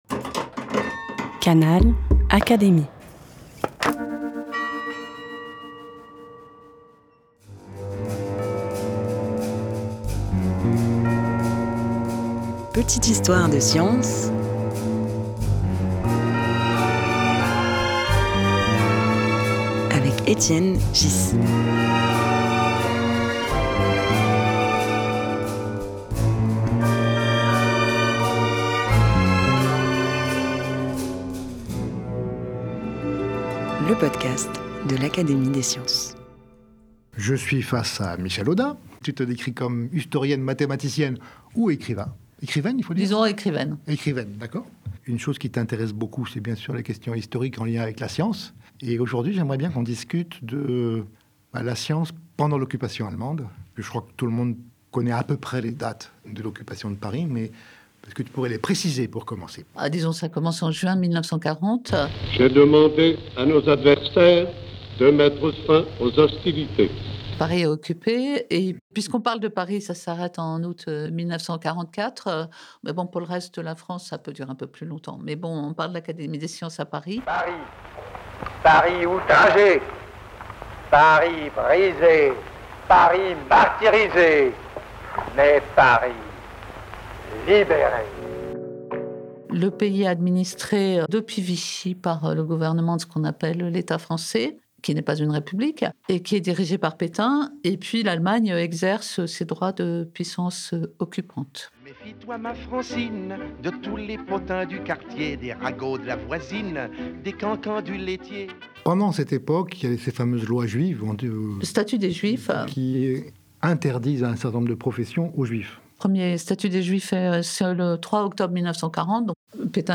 Comment cette exception a-t-elle été appliquée à l’Académie des sciences ? Au micro d’Étienne Ghys, l’historienne Michèle Audin présente plusieurs exemples et contre-exemples, de cette période trouble sous l’Occupation allemande.
Un podcast animé par Étienne Ghys, proposé par l'Académie des sciences.